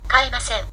ka i ma se n